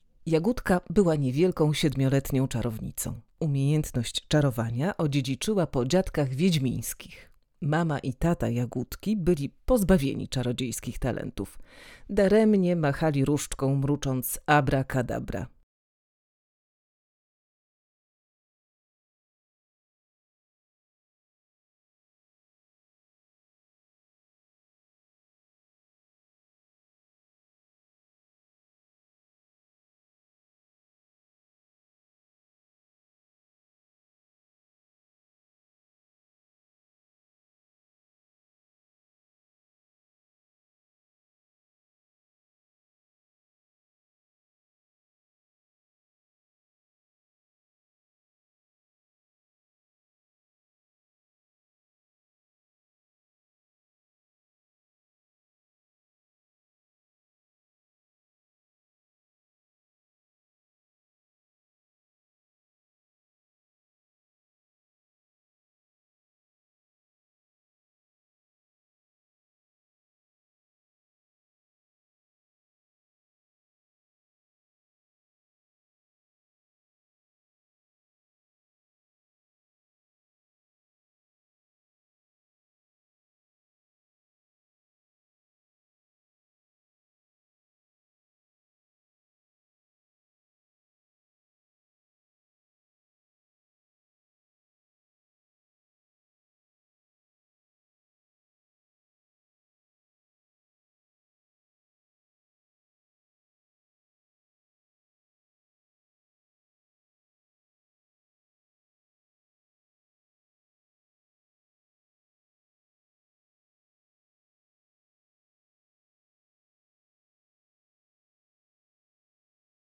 Jagódka mała czarodziejka - Joanna Olech - audiobook